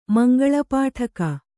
♪ mangaḷa pāṭhaka